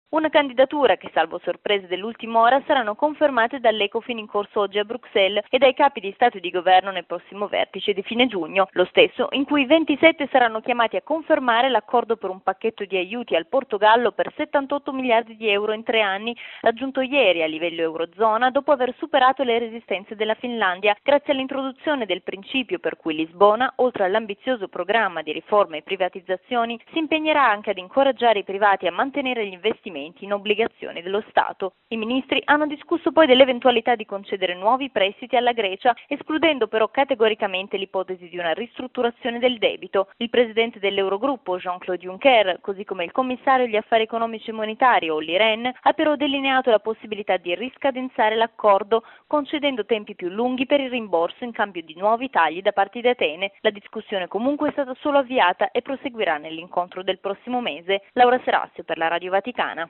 In primo piano la designazione all’unanimità da parte dei ministri dell’Eurozona del governatore di Bankitalia, Mario Draghi, come successore di Jean-Claude Trichet alla guida della Banca centrale europea. Da Bruxelles,